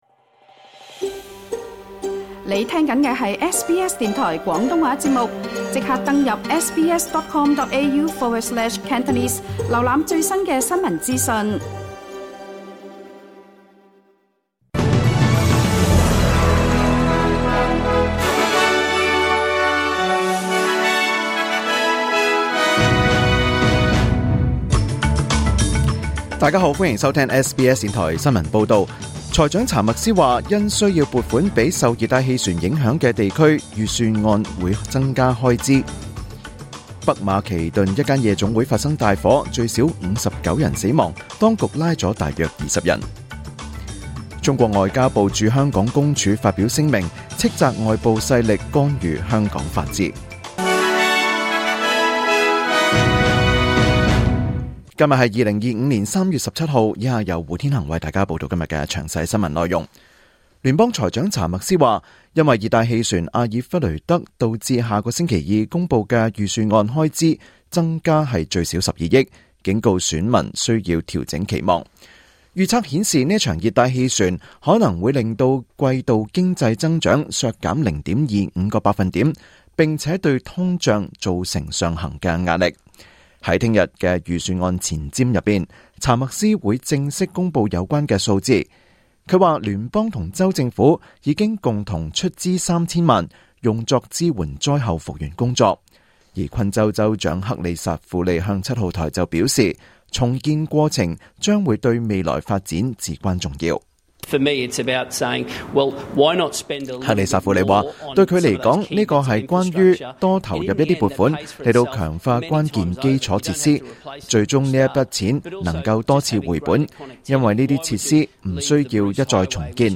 2025 年 3 月 17 日 SBS 廣東話節目詳盡早晨新聞報道。